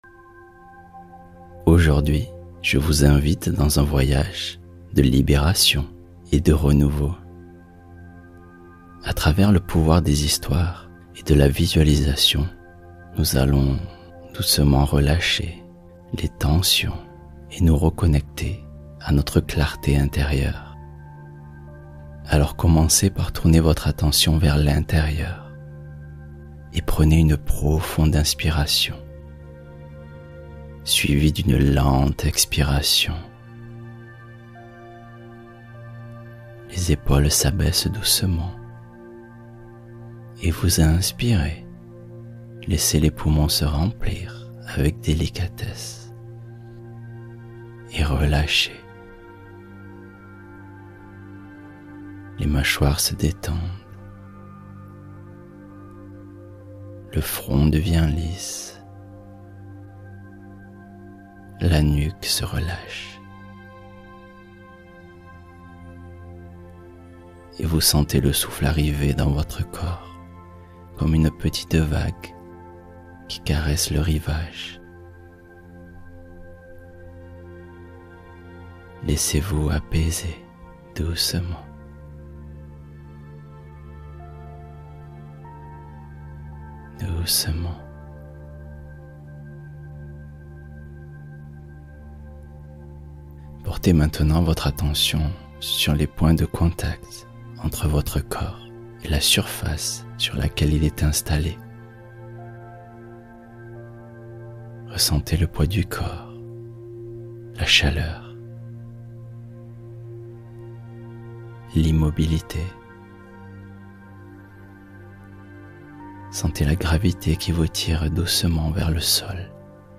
Libérer les tensions émotionnelles — Méditation douce d’apaisement